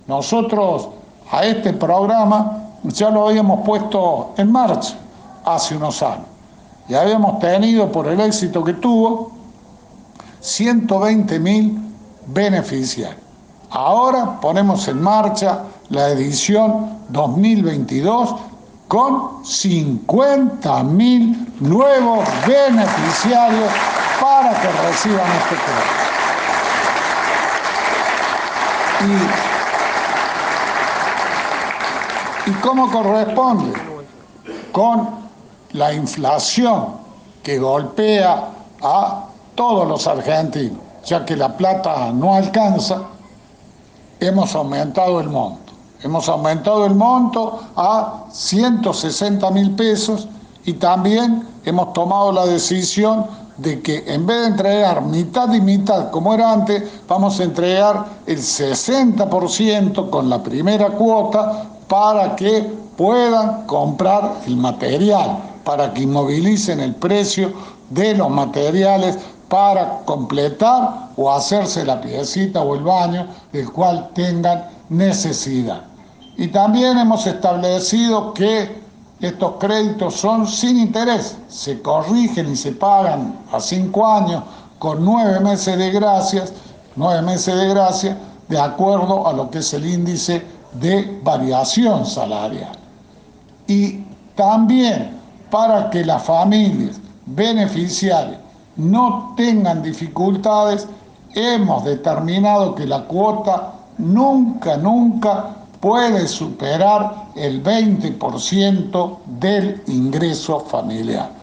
El gobernador Juan Schiaretti presentó este lunes en el Centro Cívico una nueva edición del programa Más Vida Digna, que tiene como propósito brindarles a las familias la oportunidad de construir o finalizar un baño o dormitorio.
Audio: Gobernador Juan Schiaretti.